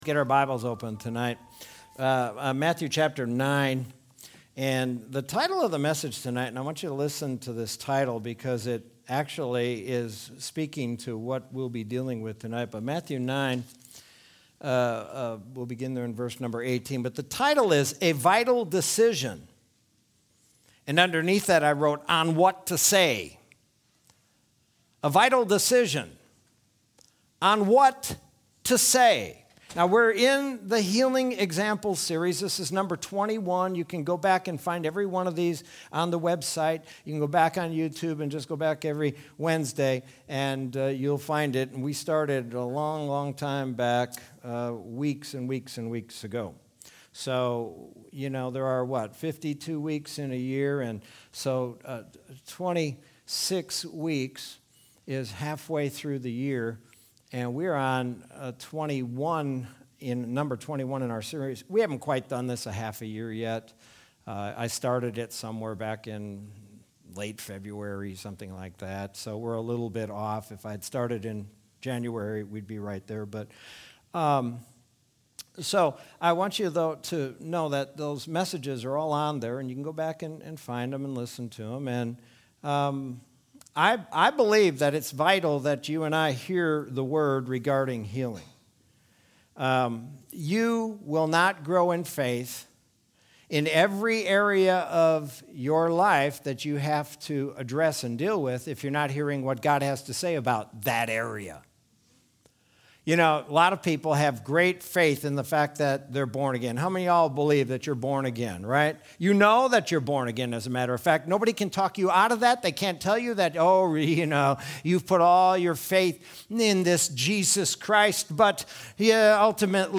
Sermon from Wednesday, July 7th, 2021.